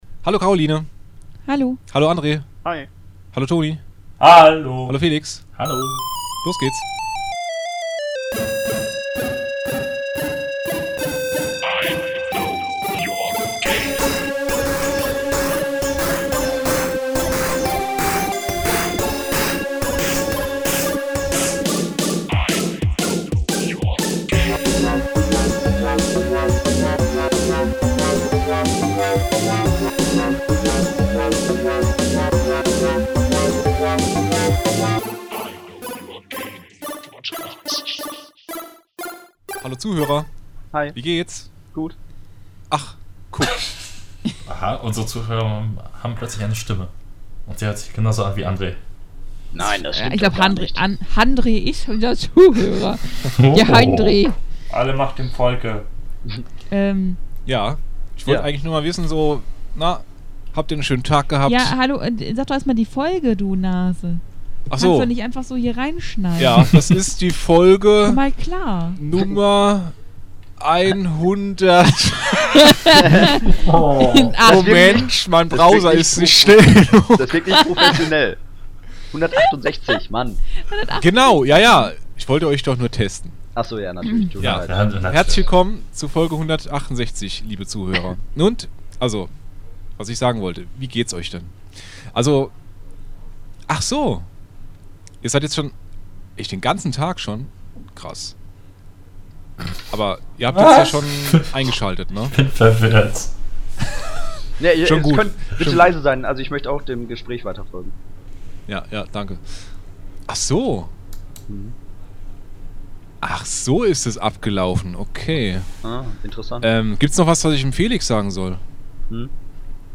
Diese Woche stürzen sich gleich fünf unserer besten Podcaster in die gnadenlose Gaming-News-Welt. Themen: SEGA und Nintendos Creator-Program.